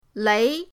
lei2.mp3